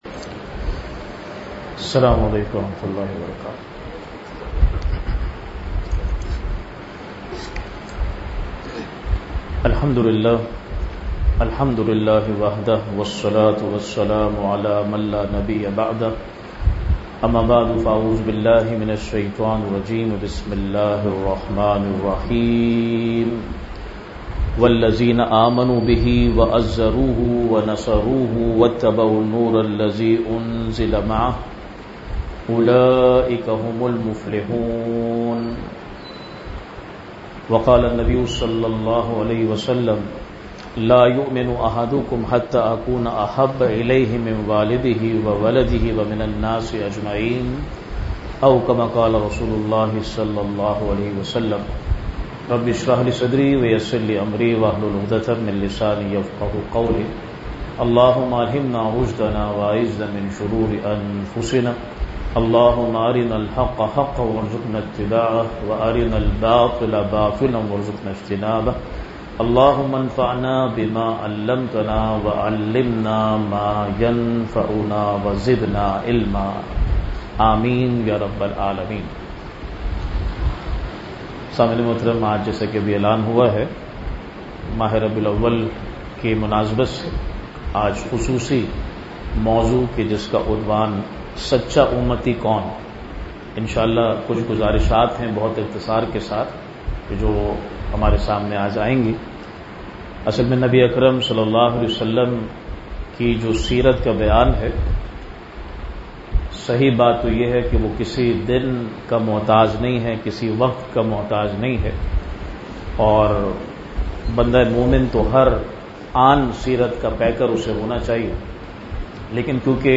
Weekly Dars-e-Quran